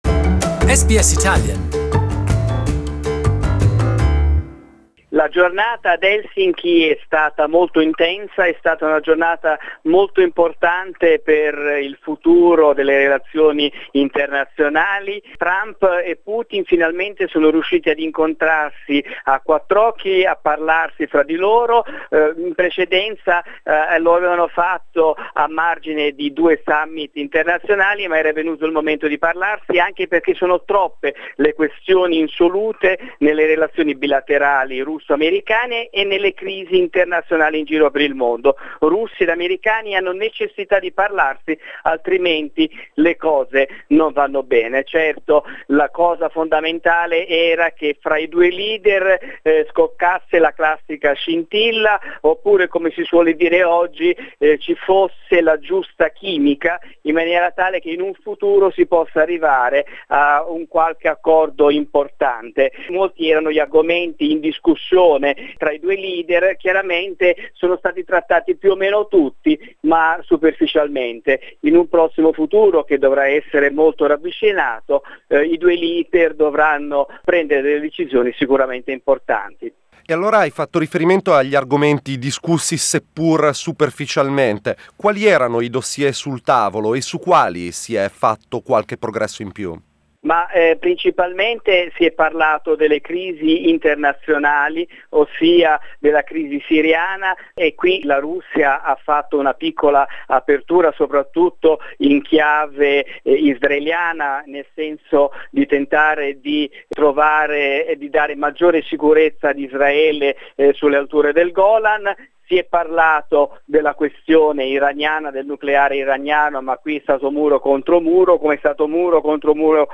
Our correspondent in Helsinki talks about the meeting between Donald Trump and Vladimir Putin.